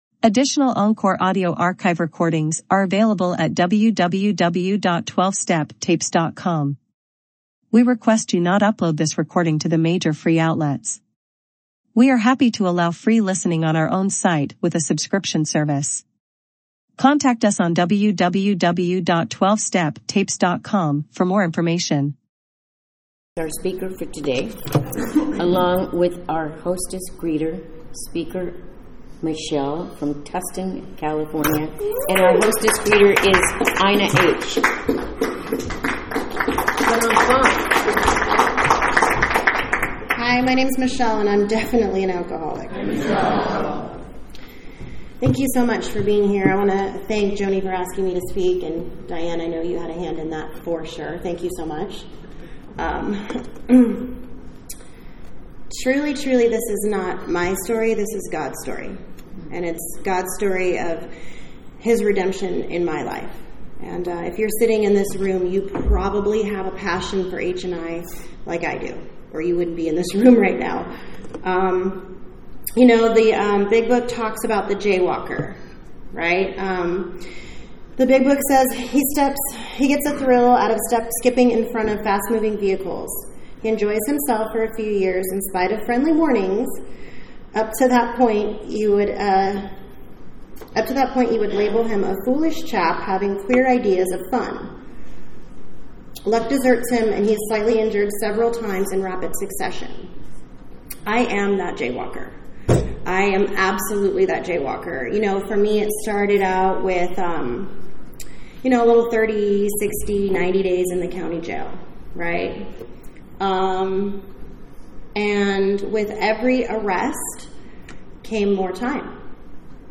San Fernando Valley AA Convention 2024 - Let Go and Let God